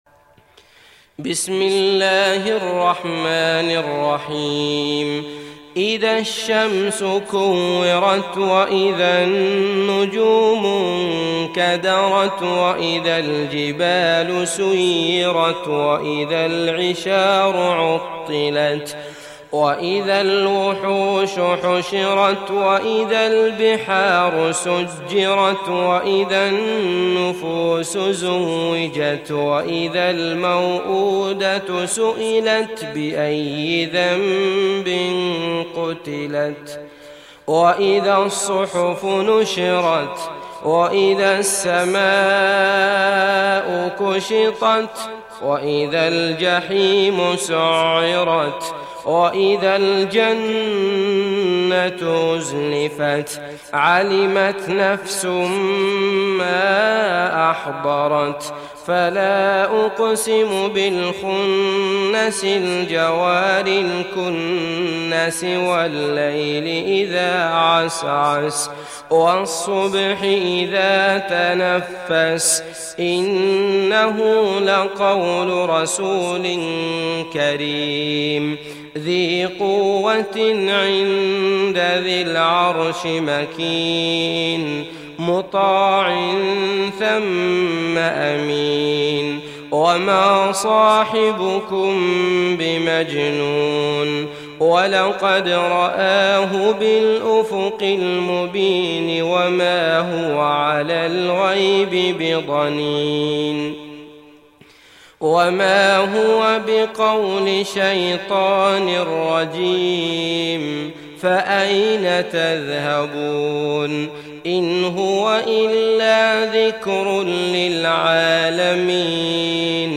Tekvir Suresi mp3 İndir Abdullah Al Matrood (Riwayat Hafs)
Tekvir Suresi İndir mp3 Abdullah Al Matrood Riwayat Hafs an Asim, Kurani indirin ve mp3 tam doğrudan bağlantılar dinle